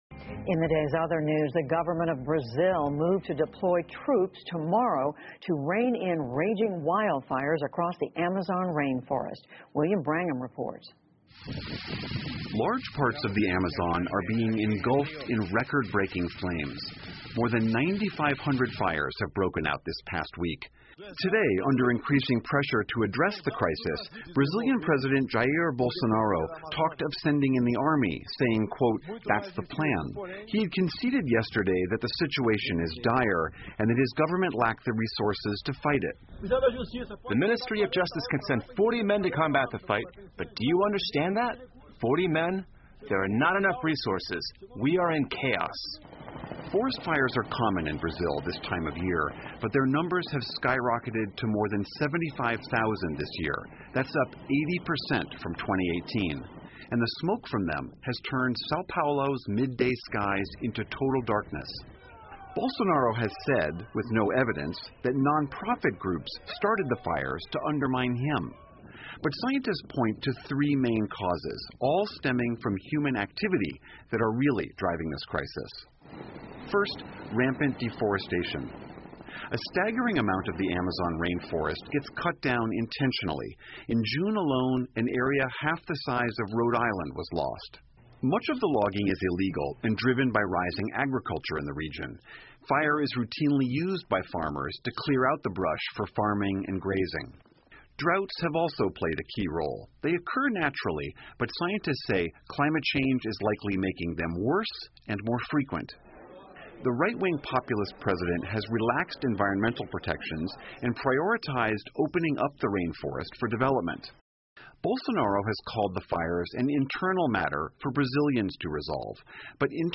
PBS高端访谈:巴西遭遇救火压力 听力文件下载—在线英语听力室